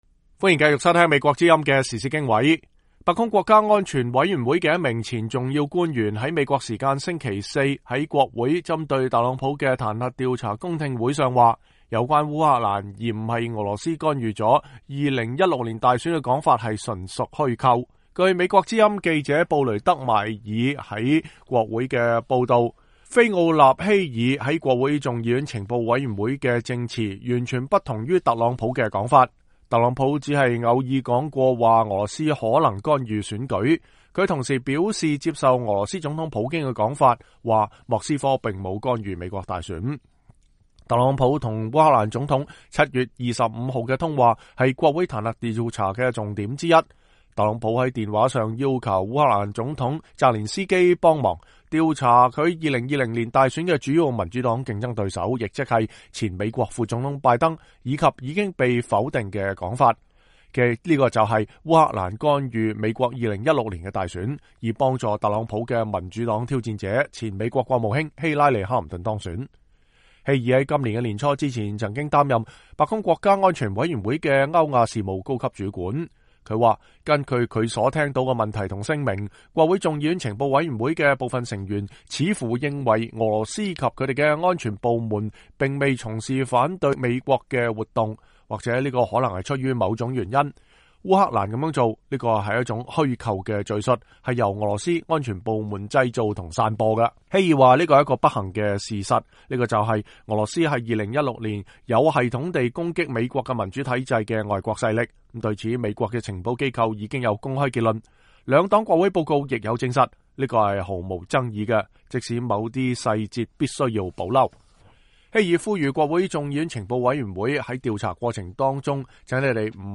菲奧納·希爾（Fiona Hill）在眾議院情報委員會作證